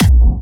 VEC3 Bassdrums Dirty 22.wav